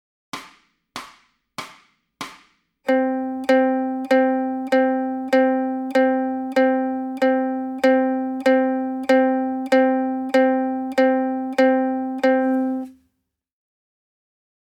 Warm-Up D1 Ton A Warm-Up D2 Ton H Warm-Up D3 Ton C Warm-Up D4 Ton E Warm-Up D5 Ton F Warm-Up D6 Ton G Warm-Up D7 Ton C tief Warm-Up D8 Ton D Warm-Up D9 G-Saite Warm-Up D10 ¾-Takt Warm-Up D11 Ton B Warm-Up D12 Ton Fis
D7_Ton_C_tief_Warm-up.mp3